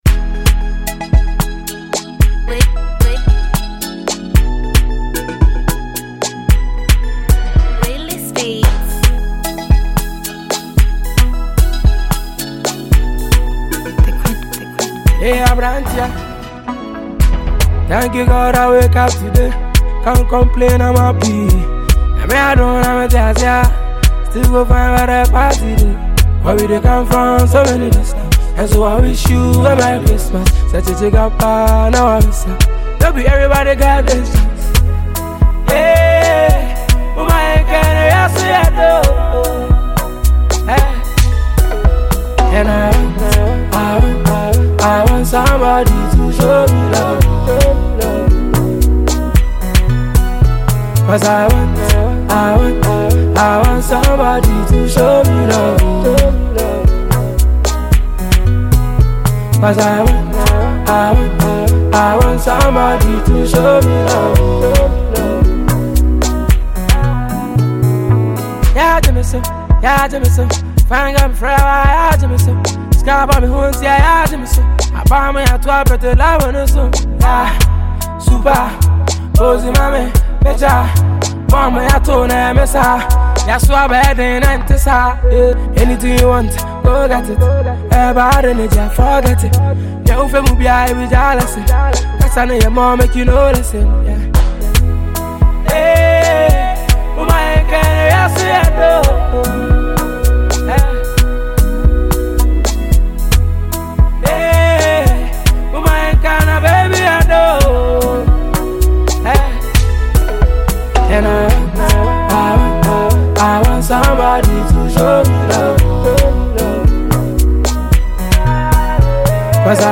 Multiple award-winning Ghanaian singer and songwriter
catchy new single